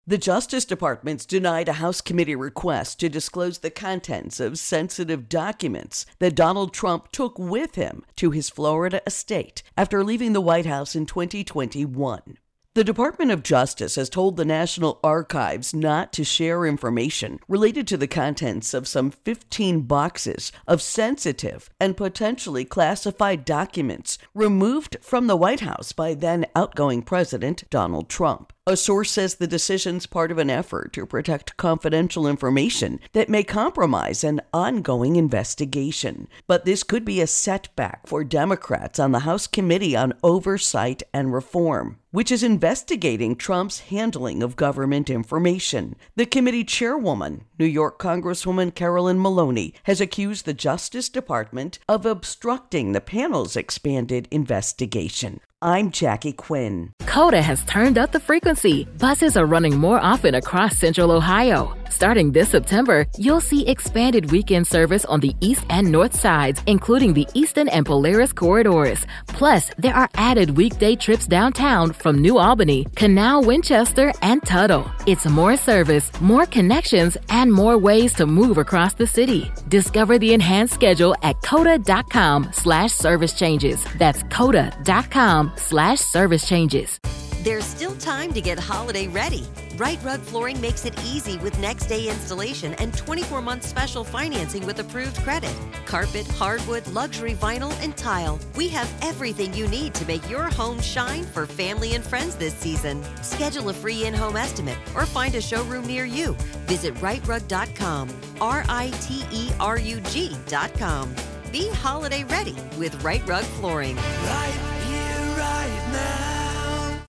National Archives Trump Intro and Voicer